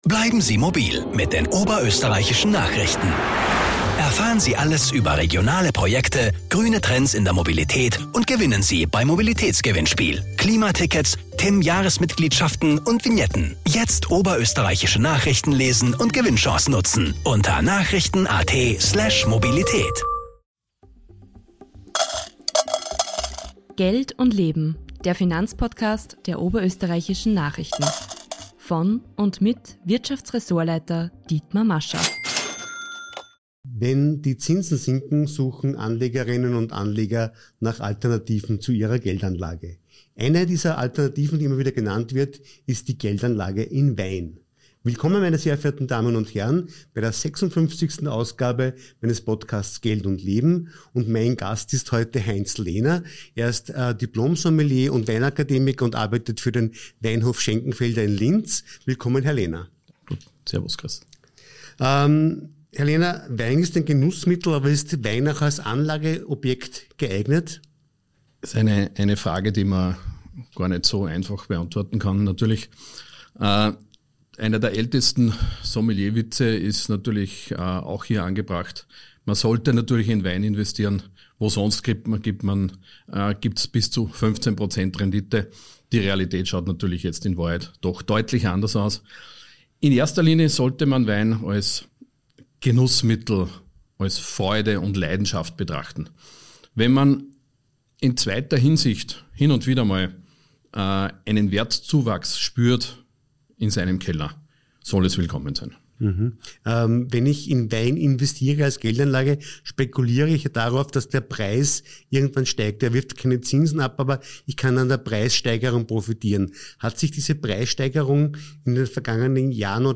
im Gespärch